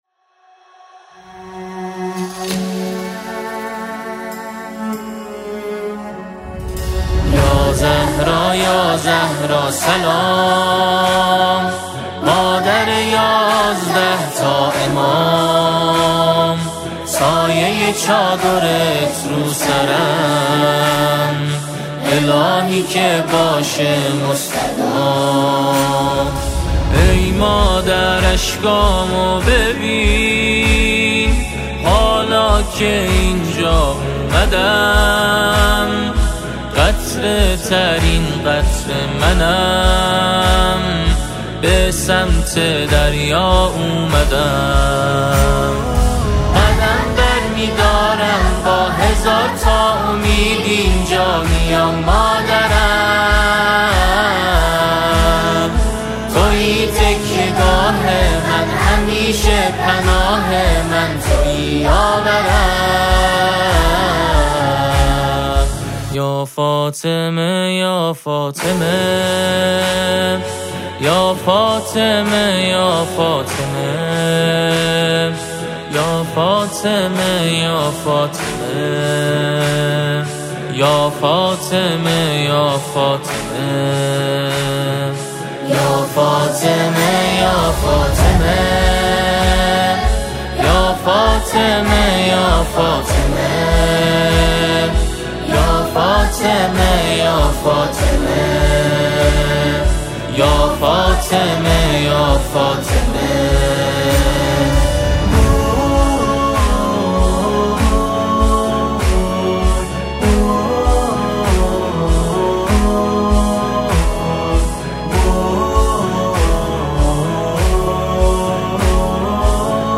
نماهنگ سرود